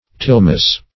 Search Result for " tilmus" : The Collaborative International Dictionary of English v.0.48: Tilmus \Til"mus\, n. [NL., fr. Gr. tilmo`s, fr. ti`llein to pluck, pull.]